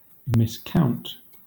Ääntäminen
Ääntäminen Southern England Southern England Tuntematon aksentti: IPA : /mɪsˈkaʊnt/ Haettu sana löytyi näillä lähdekielillä: englanti Kieli Käännökset saksa verzählen Määritelmät Verbi To count incorrectly.